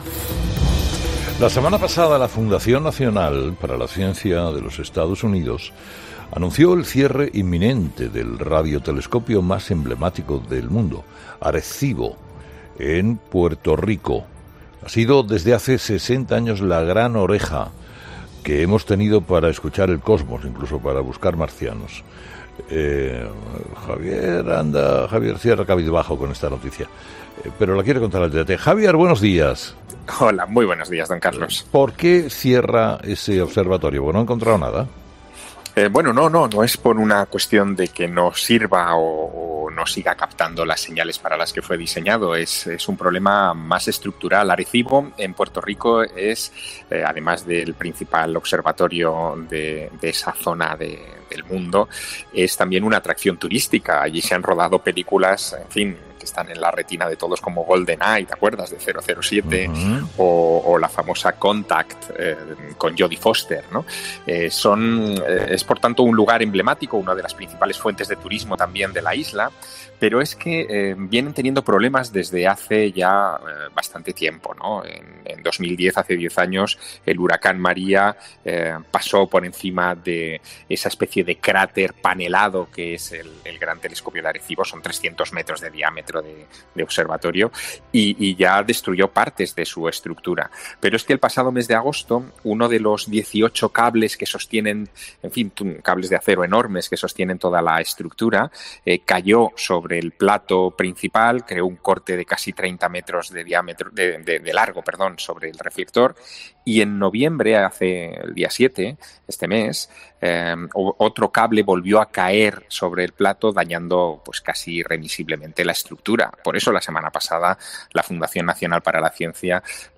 Javier Sierra nos habla de los "radiotelescopios" que sirven para escuchar señales del Cosmos y demostrar que realmente existen otras civilizaciones extraterrestres vivas en distintas galaxias.